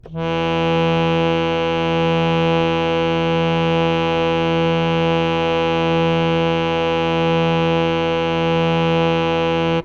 samples / harmonium / E2.wav
E2.wav